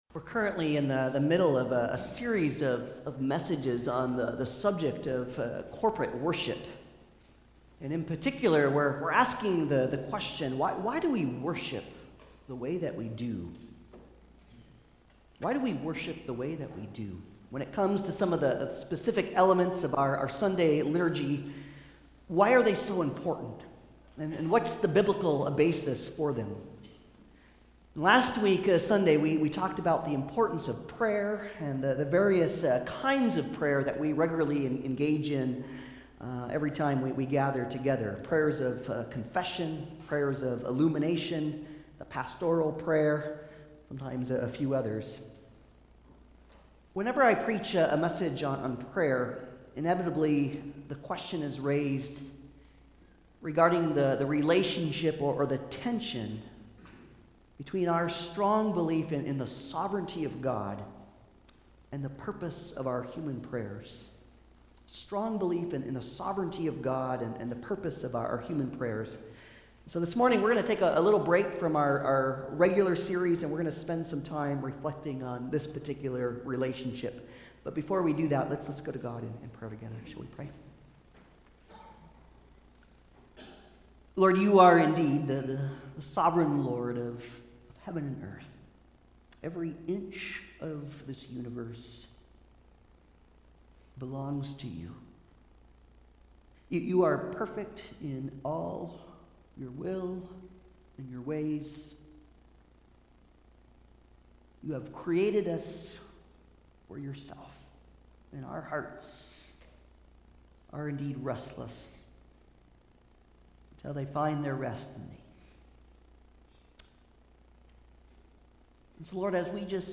Colossians 4:2-6 Service Type: Sunday Service « Prayer God’s Possession »